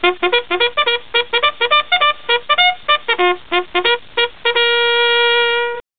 Fanfare klingelton kostenlos
Kategorien: Soundeffekte